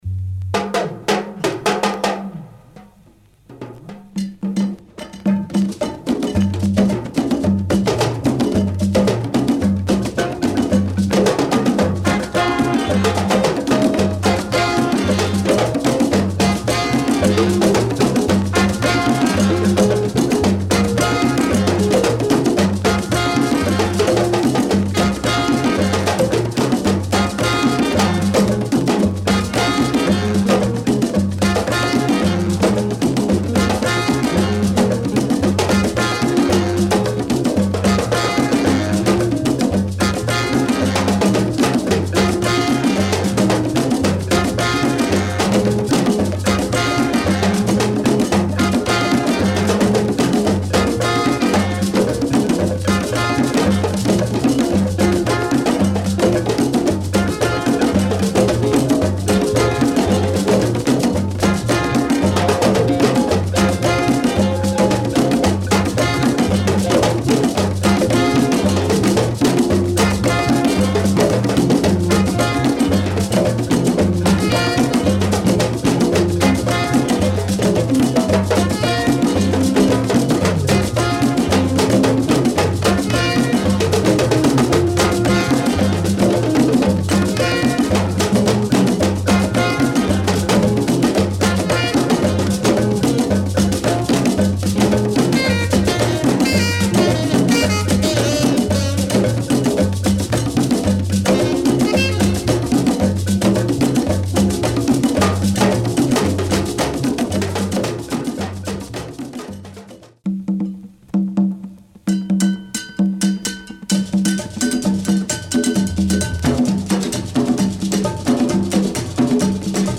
Sublime afro jazz / highlife session recorded in London